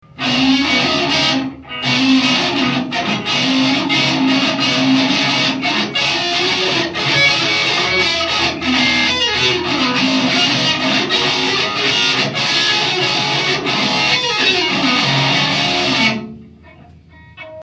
Hangminták: